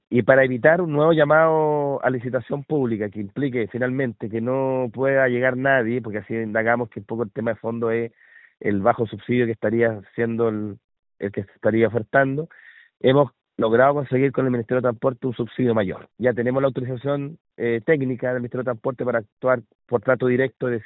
En conversación con La Radio, el delegado Presidencial, Jorge Alvial, indicó que se gestionó un aumento en el subsidio del Estado para que haya interesados en operar la barcaza.